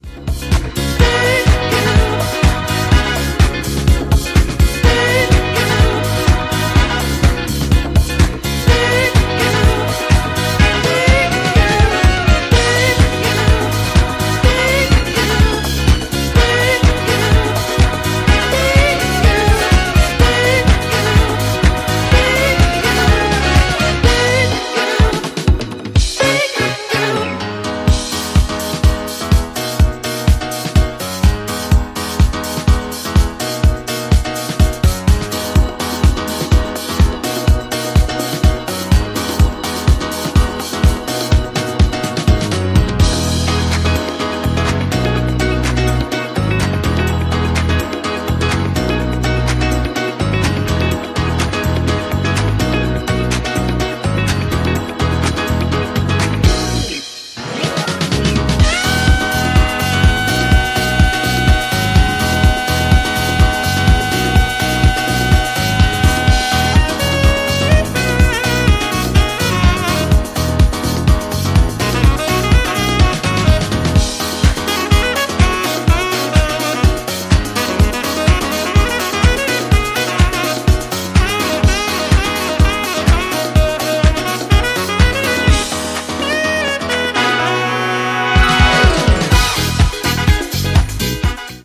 all-time house classic